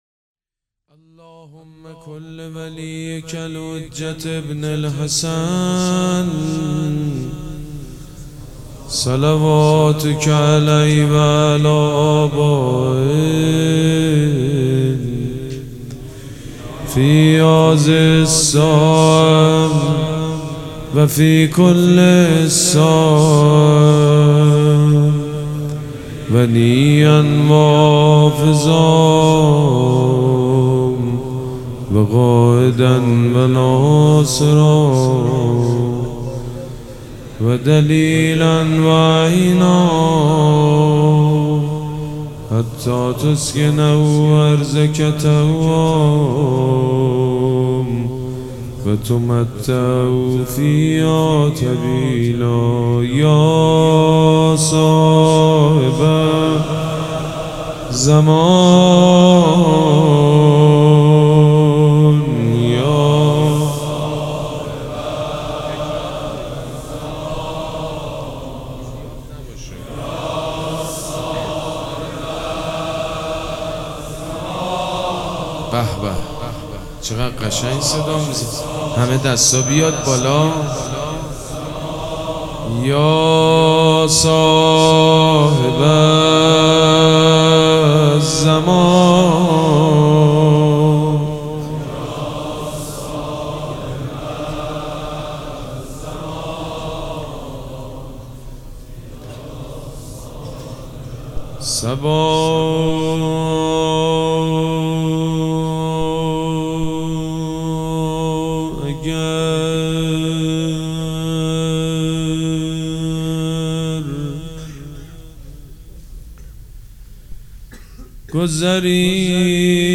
شب شهادت امام حسن عسکری ۹۸
روضه
مداح